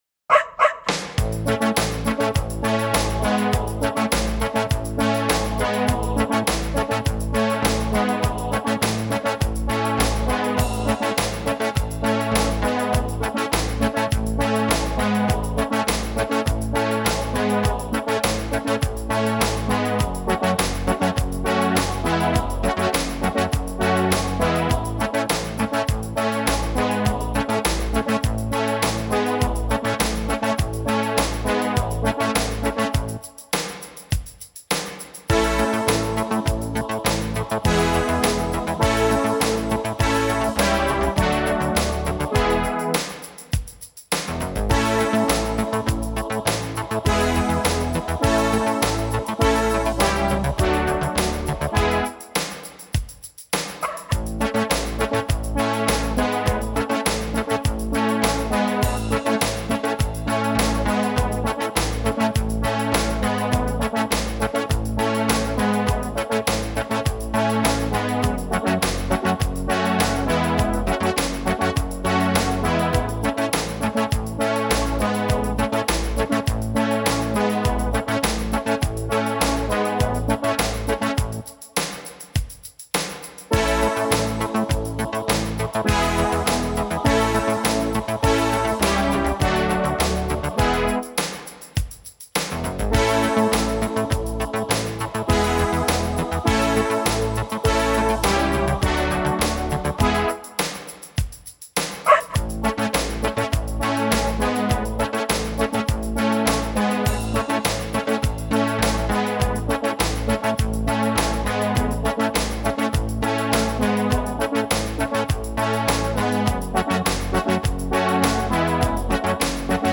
Übungsaufnahmen - Ba-Ba-Banküberfall
Runterladen (Mit rechter Maustaste anklicken, Menübefehl auswählen)   Ba-Ba-Banküberfall (Playback)